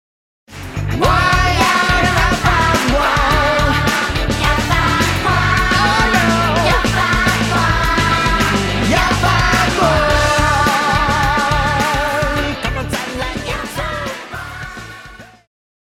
Pop chorus,Children Voice
Band
Hymn,POP,Christian Music
Voice with accompaniment
饒舌（Rap）是起源與美國非裔移民的傳統演唱藝術，一種帶有節奏與押韻的說唱方式。